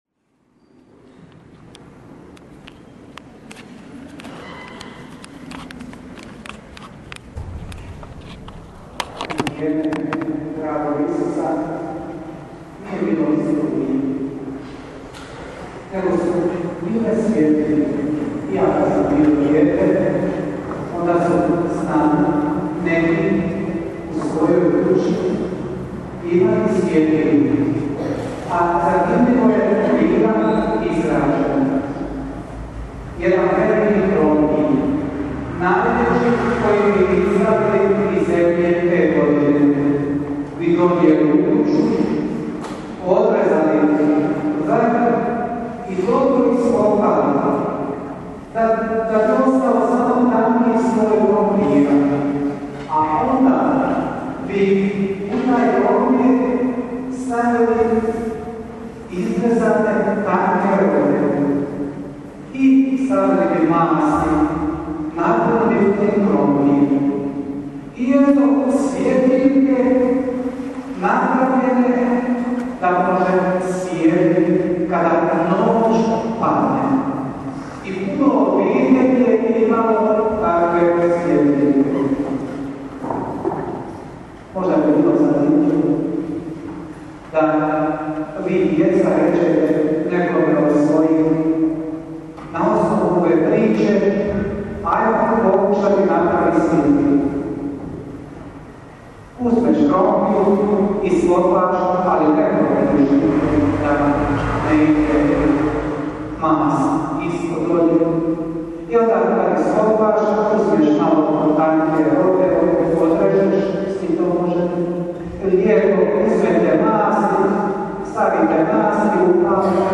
PROPOVJED: